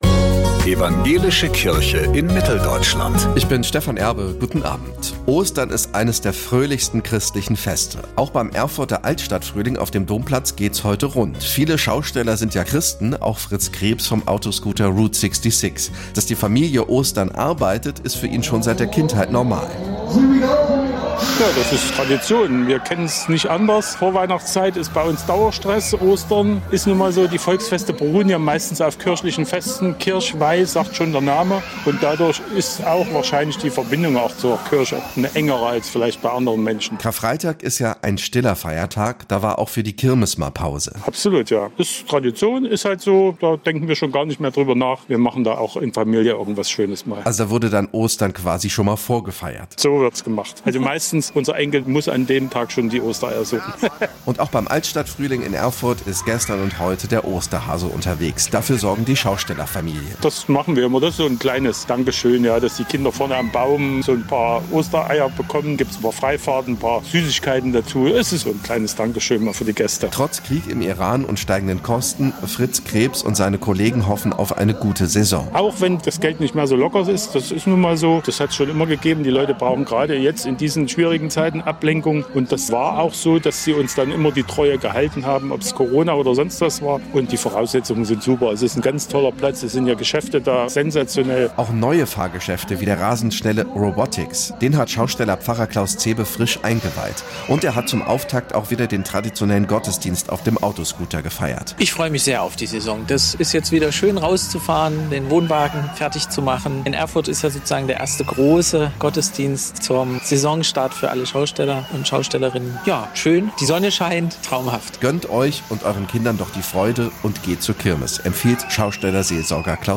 Ostern ist eines der fröhlichsten christlichen Feste. Auch beim Erfurter „Altstadtfrühling“ auf dem Domplatz geht’s heute rund.